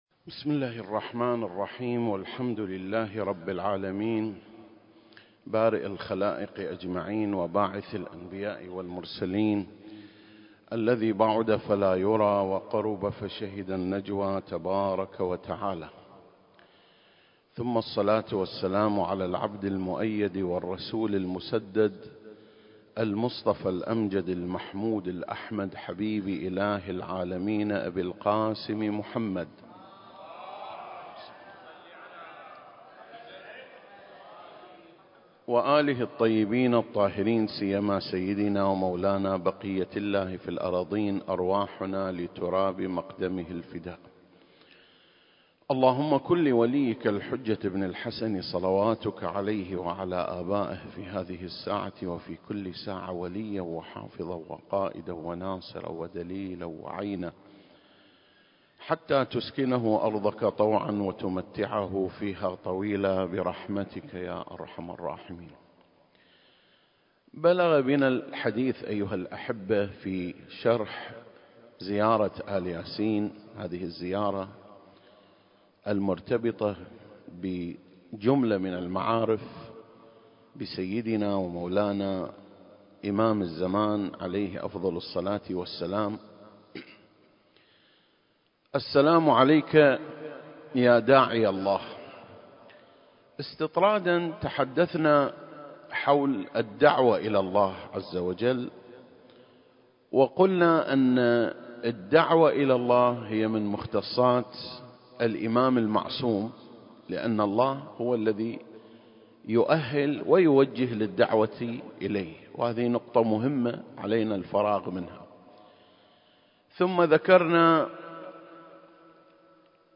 سلسلة: شرح زيارة آل ياسين (30) - سعة الدعوة المهدوية (4) المكان: مسجد مقامس - الكويت التاريخ: 2021